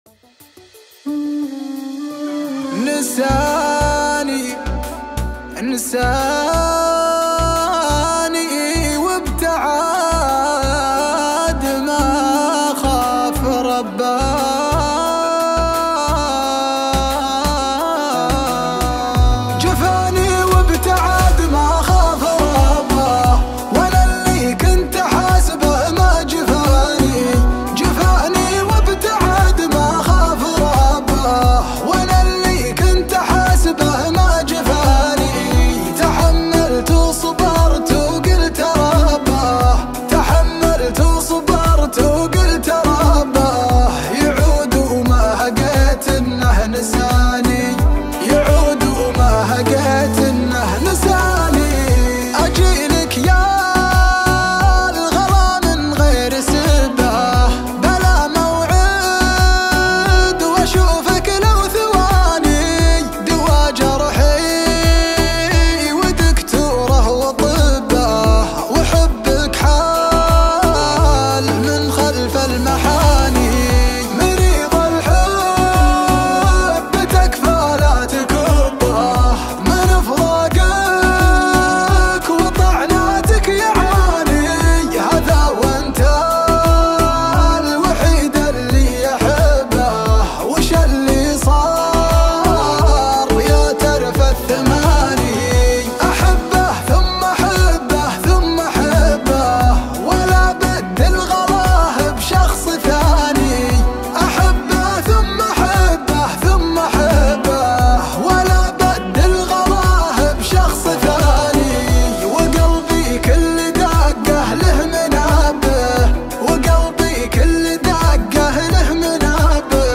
شيلات حزينة